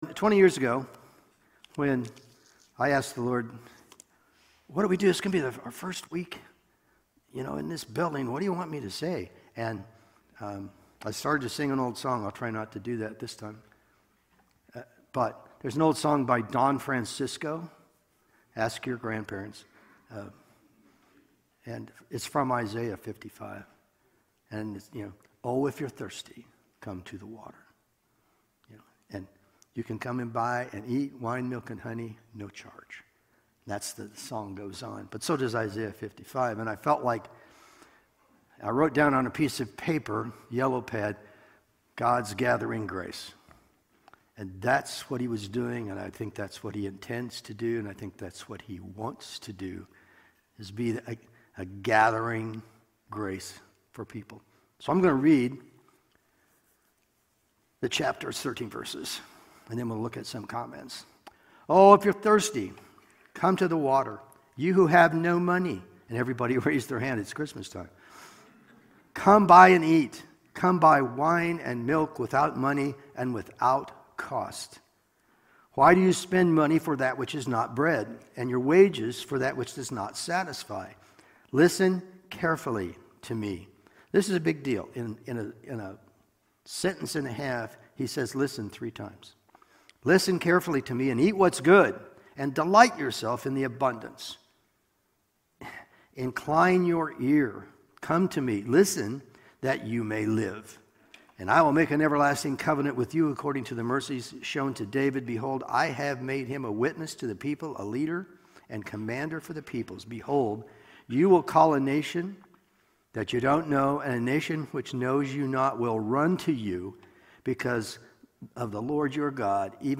Sunday Morning Sermon Download Files Notes